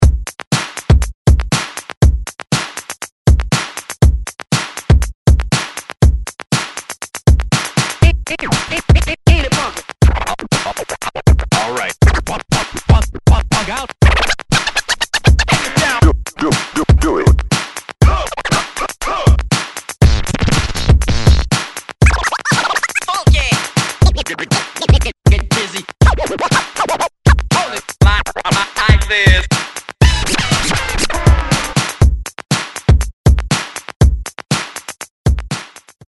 96Bpm Clean Genre: 90's Version: Clean BPM: 96 Time